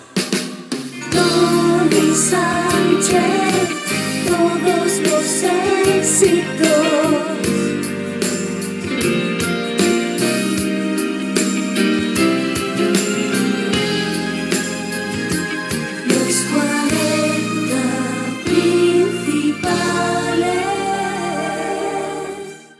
Indicatiu del programa
FM